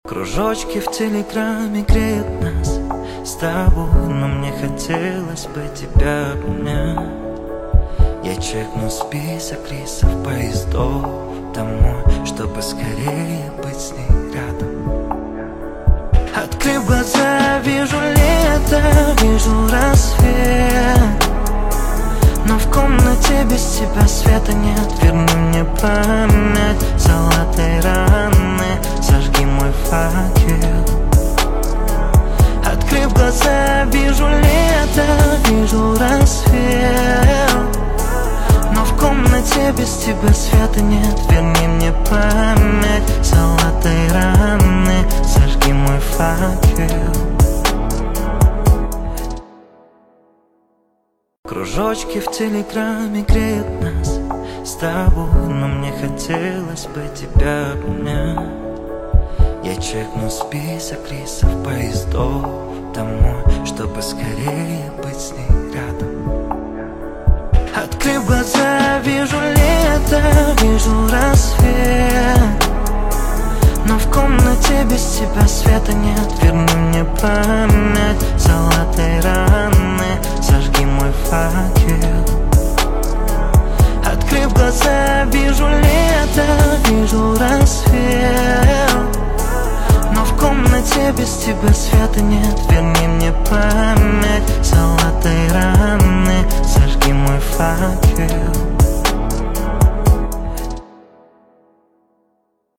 Качество: 320 kbps, stereo